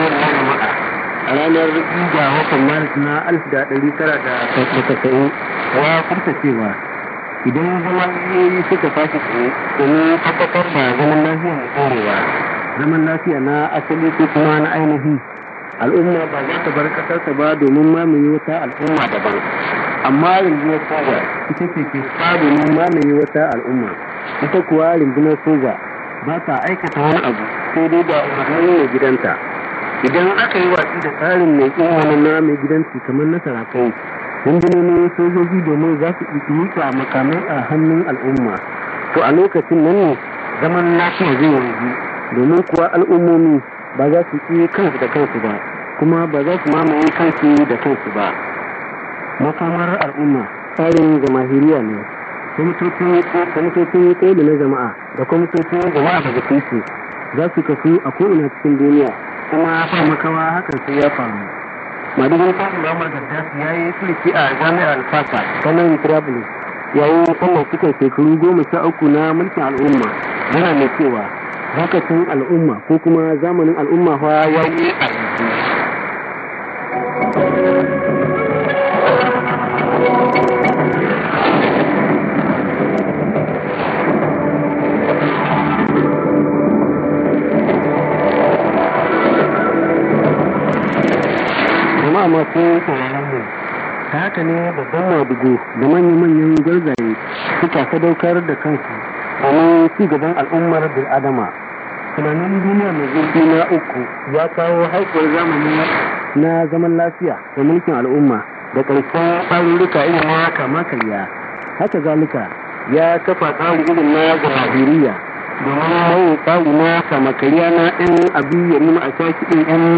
ST: signature tune/jingle